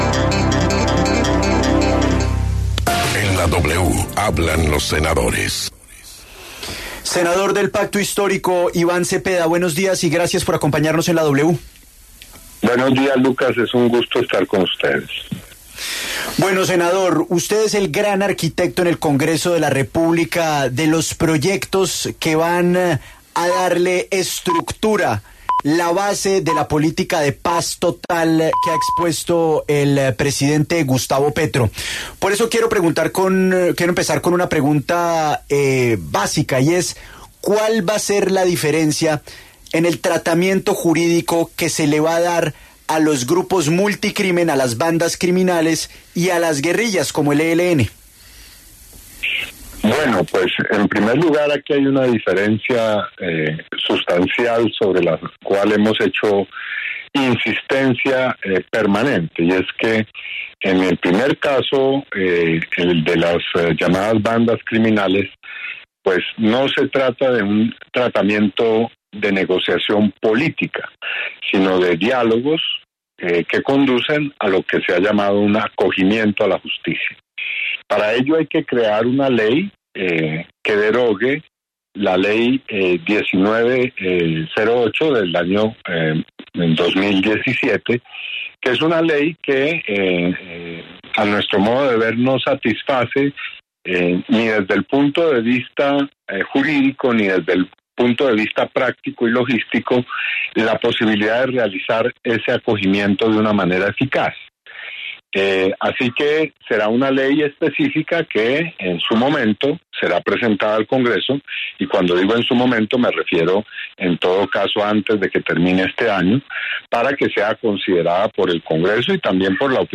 Iván Cepeda, senador del Pacto Histórico, explicó los detalles de la iniciativa para que los grupos armados organizados se puedan acoger a la justicia.